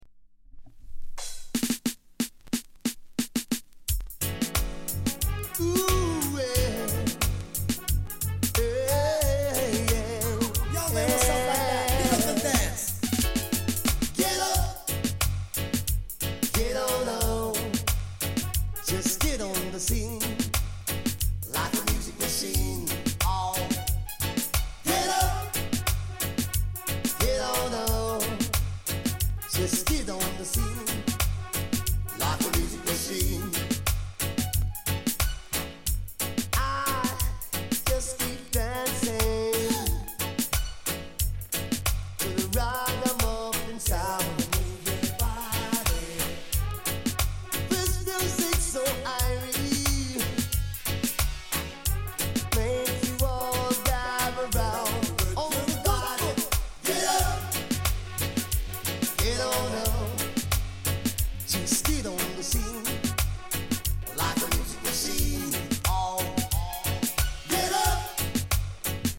R＆B～LOVERS
音に影響ない 軽い反り。